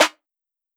Snares
BlockaSnare_MJ.wav